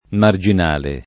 [ mar J in # le ]